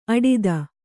♪ aḍida